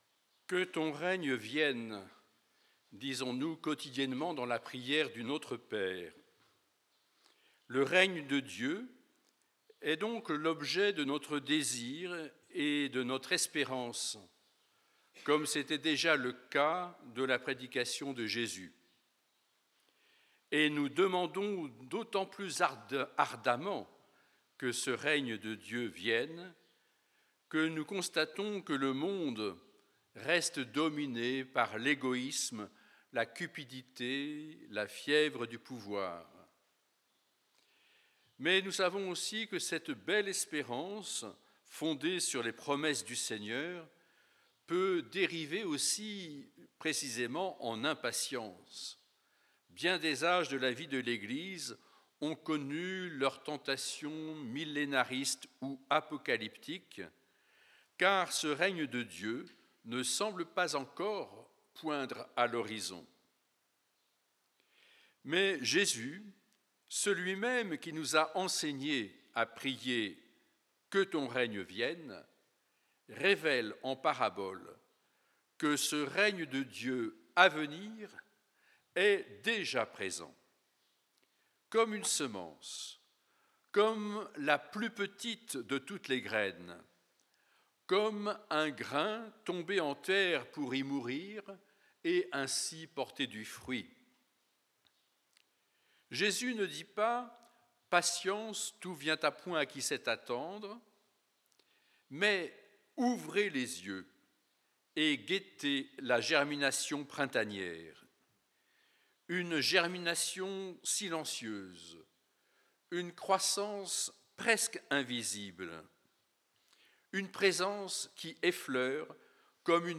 Homélie
Ce matin, au couvent St-Hyacinthe, les frères ont médité l'Évangile selon saint Marc (4:26-34)